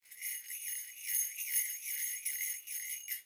finger_cymbals_rub
bell chime cymbal ding finger-cymbals orchestral percussion sound effect free sound royalty free Sound Effects